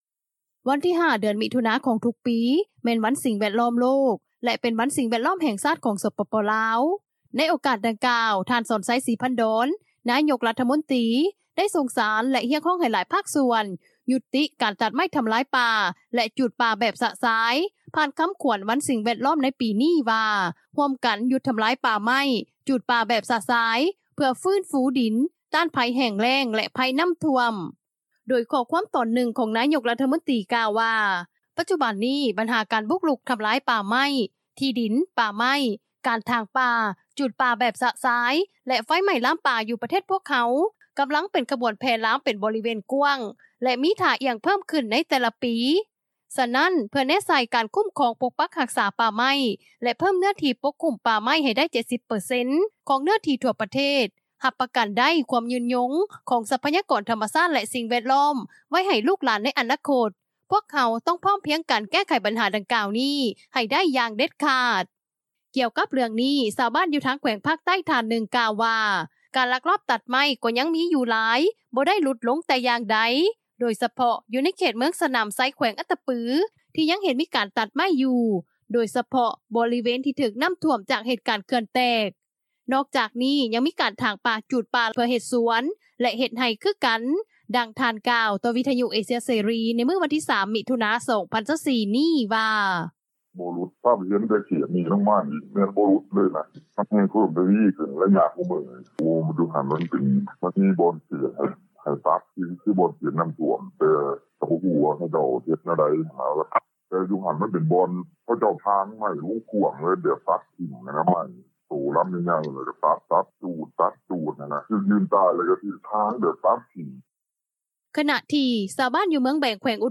ດັ່ງຜູ້ຊ່ຽວຊານ ດ້ານການສິ່ງແວດລ້ອມ ແລະການພັດທະນາແບບຍືນຍົງ ທ່ານໜຶ່ງ ກ່າວວ່າ: